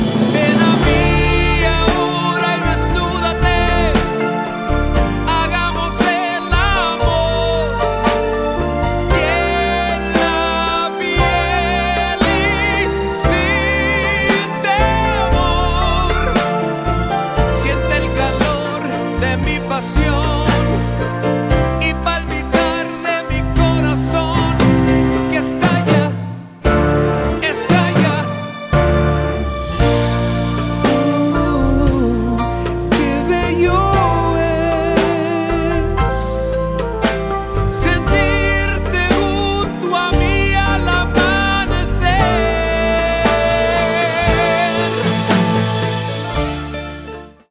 LATIN TROPICAL SOUL EXPERIENCE